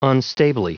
Prononciation du mot unstably en anglais (fichier audio)
Prononciation du mot : unstably